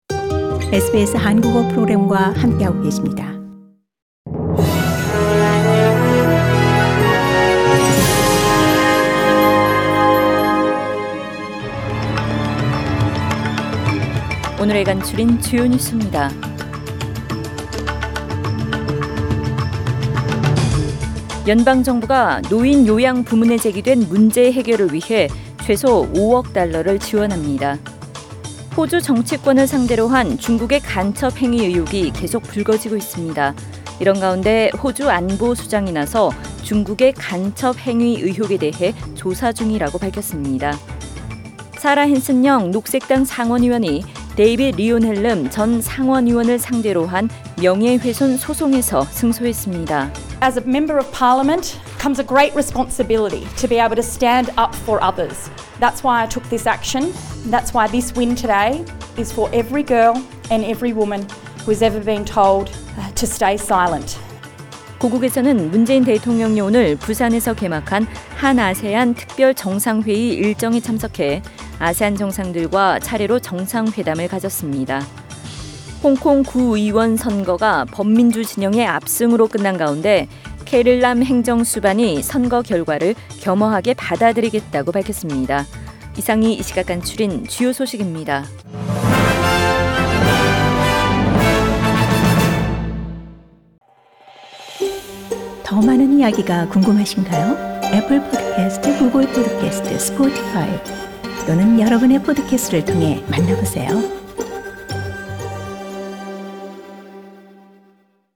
SBS Korean News Source: SBS Korean